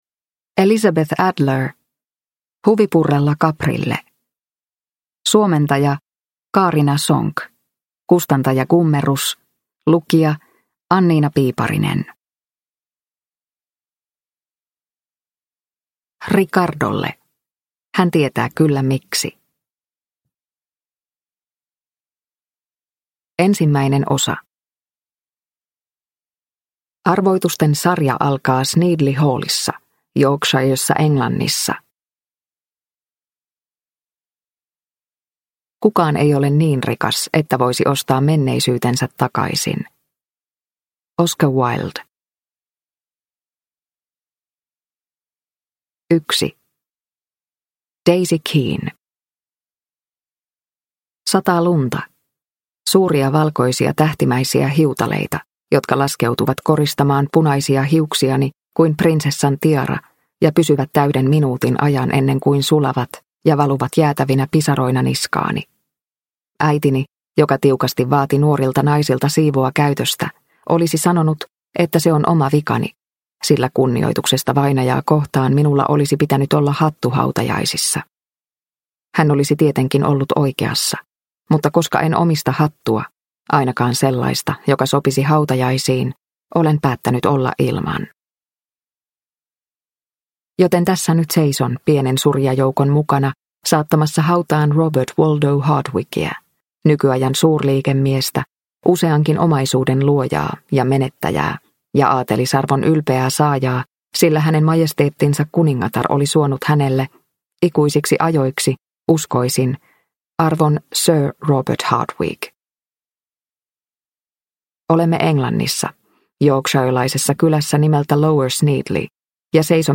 Huvipurrella Caprille – Ljudbok – Laddas ner